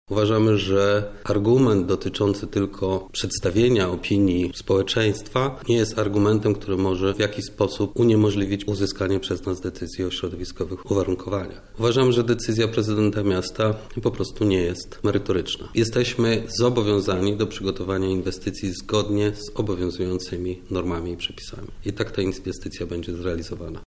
Elektrociepłownia